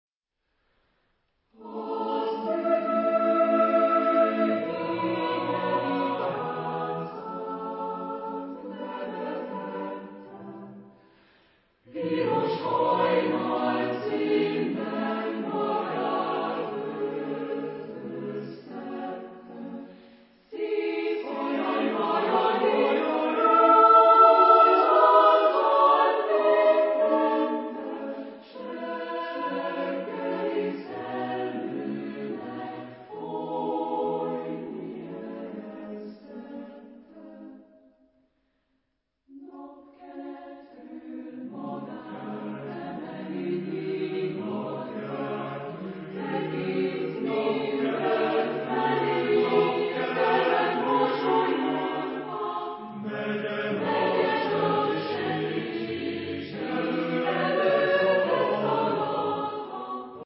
Genre-Style-Forme : Profane ; Lyrique ; Chœur
Type de choeur : SATB  (4 voix mixtes )
Tonalité : tonal